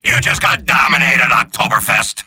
Robot-filtered lines from MvM.